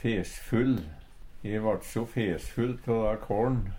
fesfull - Numedalsmål (en-US)